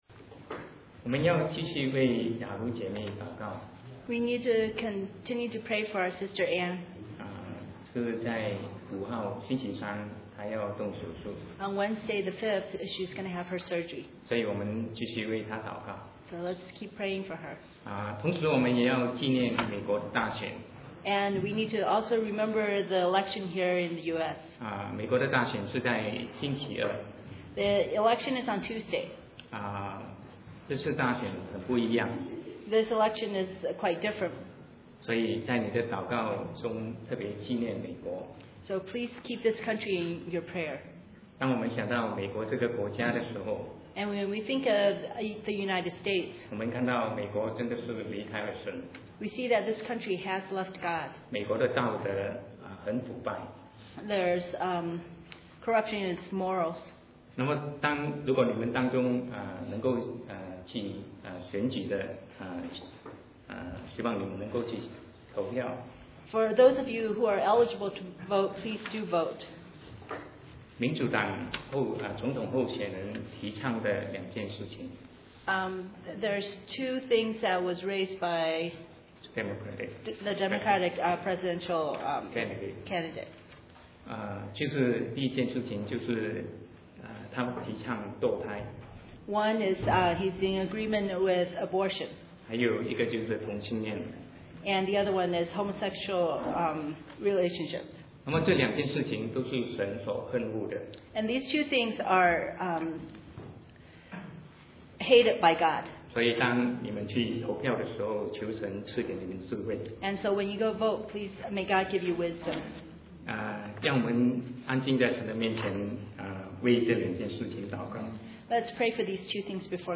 Sermon 2008-11-02 The Responsibility and Role of Husband and Wife